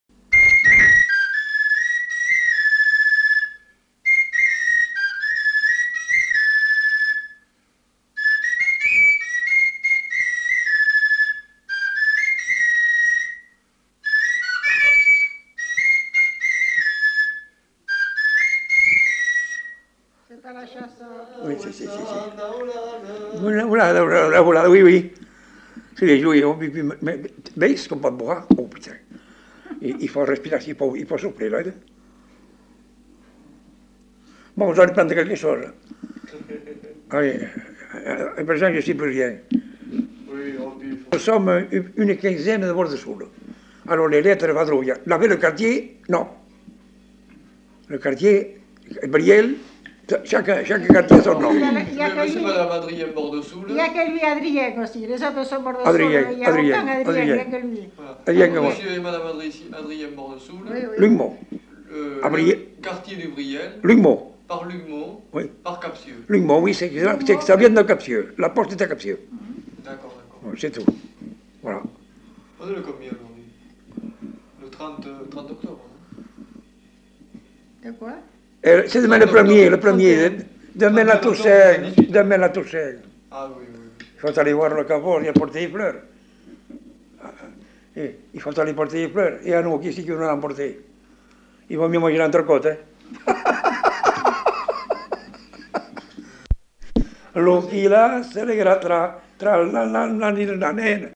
Aire culturelle : Bazadais
Genre : morceau instrumental
Instrument de musique : flûte à trois trous